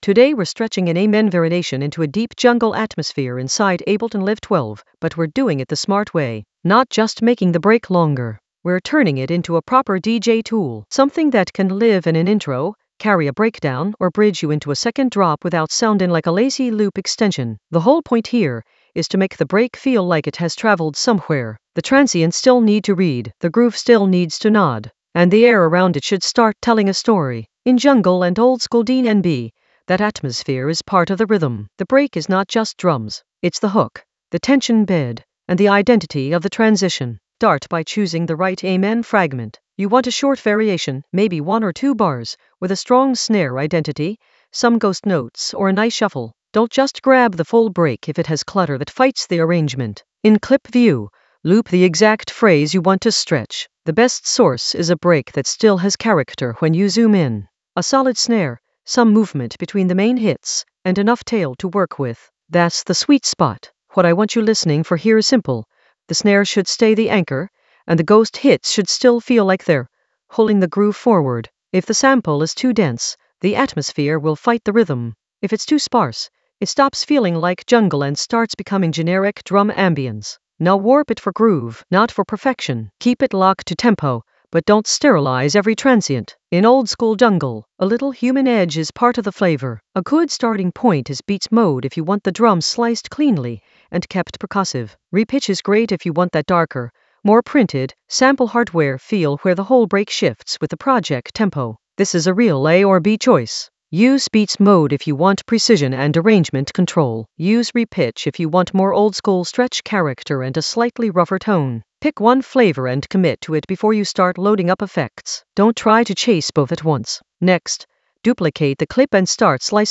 An AI-generated advanced Ableton lesson focused on Stretch an amen variation with deep jungle atmosphere in Ableton Live 12 for jungle oldskool DnB vibes in the DJ Tools area of drum and bass production.
Narrated lesson audio
The voice track includes the tutorial plus extra teacher commentary.